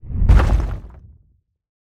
sfx_combat_rockfall.ogg